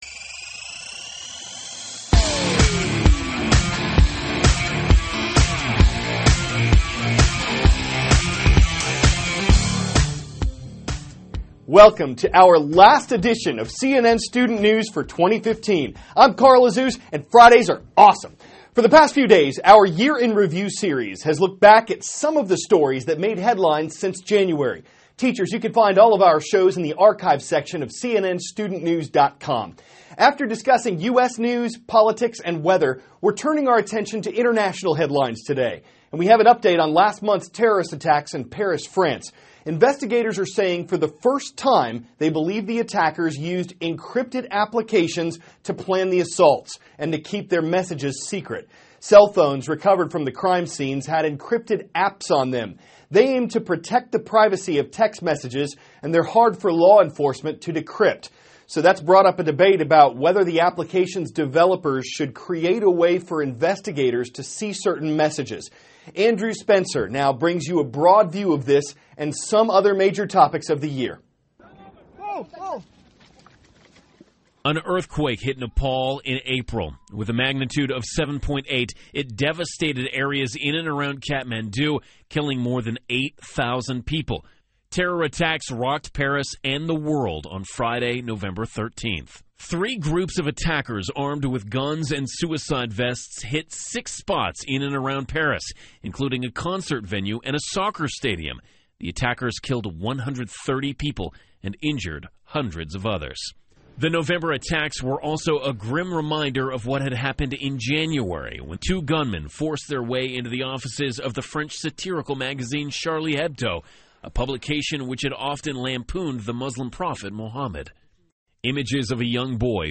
*** CARL AZUZ, cnn STUDENT NEWS ANCHOR: Welcome to our last edition of cnn STUDENT NEWS for 2015.